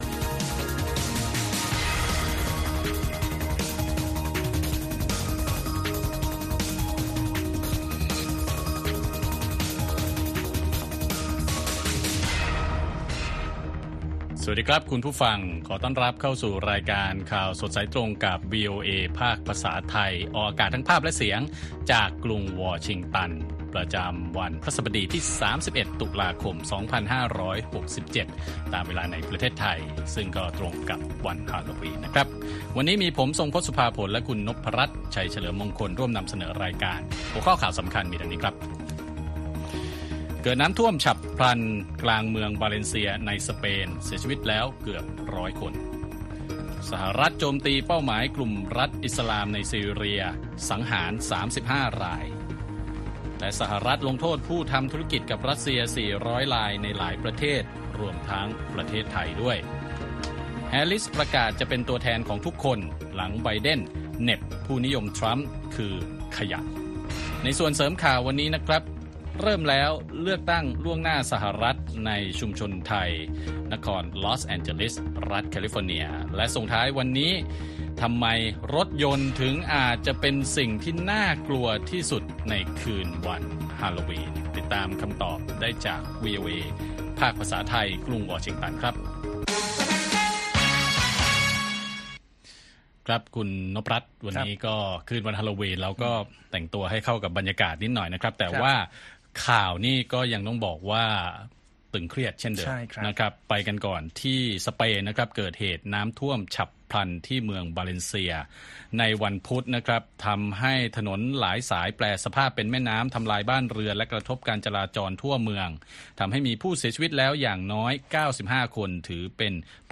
ข่าวสดสายตรงจากวีโอเอไทย ประจำวันพฤหัสบดี ที่ 31 ตุลาคม 2567